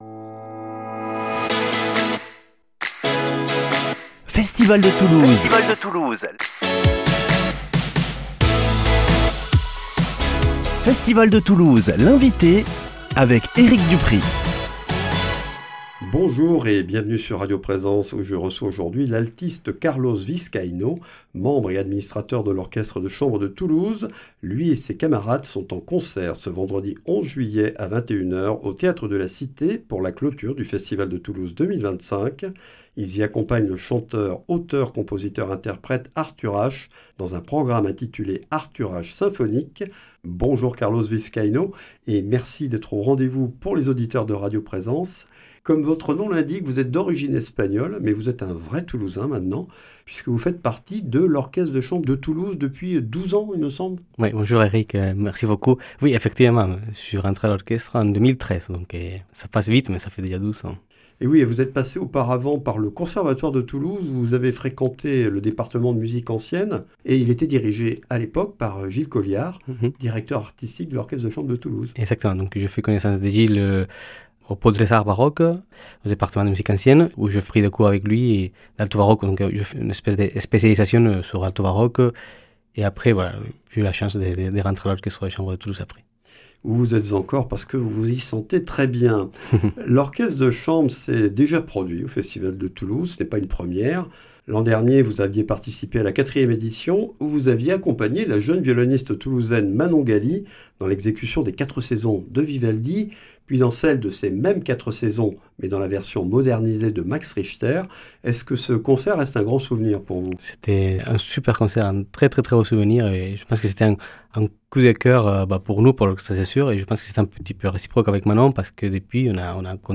Festival de Toulouse - Interviews